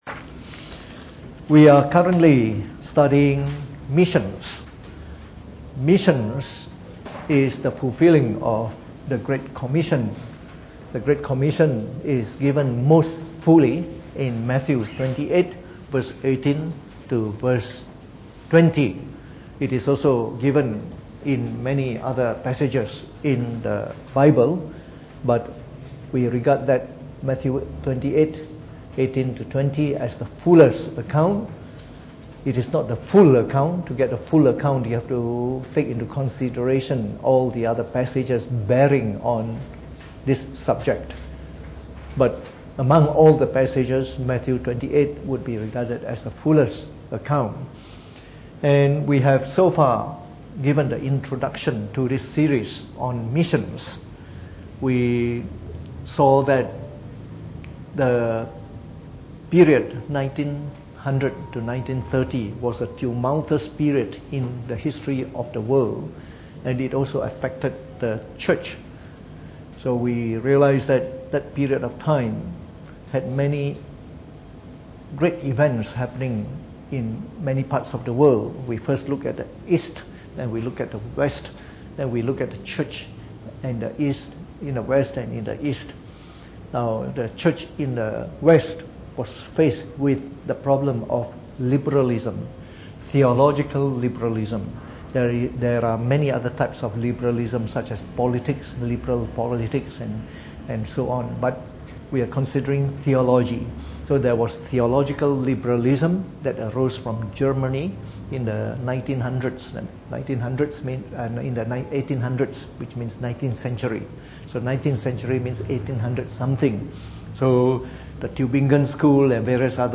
Preached on the 21th of November 2018 during the Bible Study, from our series on Missions.